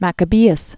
(măkə-bēəs), Judas or Judah Died 160 BC.